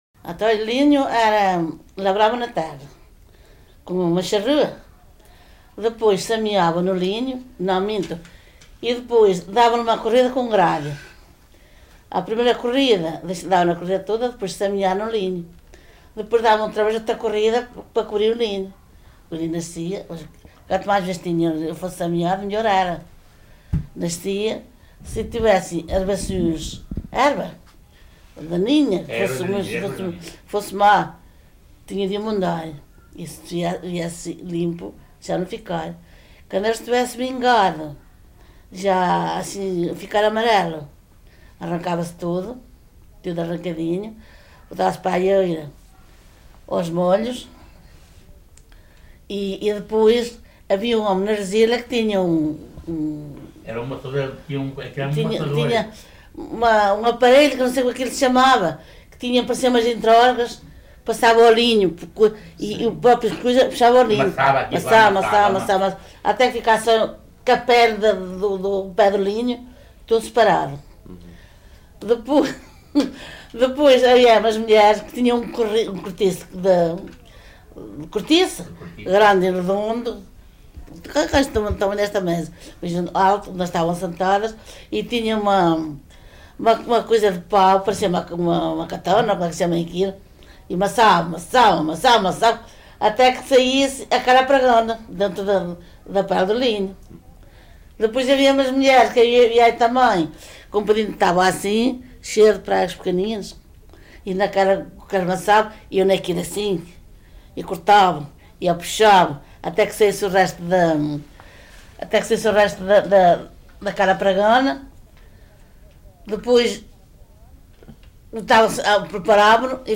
LocalidadeVila Pouca do Campo (Coimbra, Coimbra)